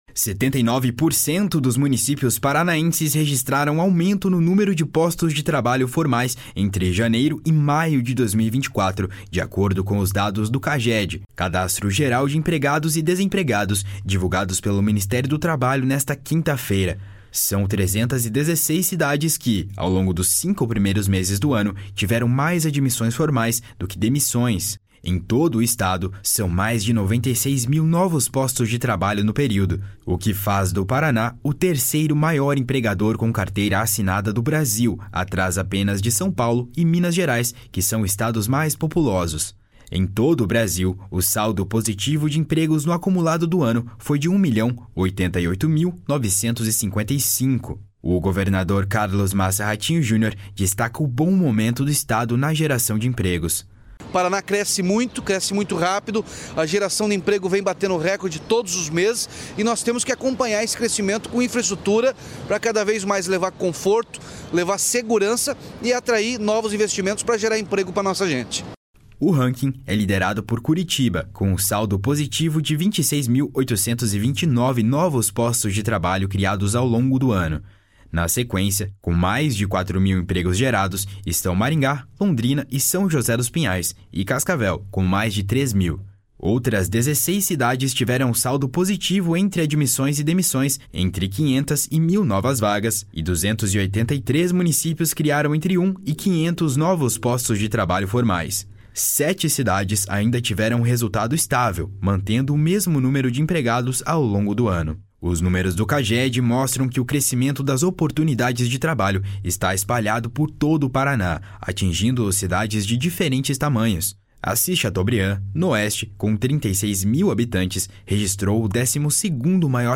O governador Carlos Massa Ratinho Junior destaca o bom momento do estado na geração de empregos. // SONORA RATINHO JUNIOR //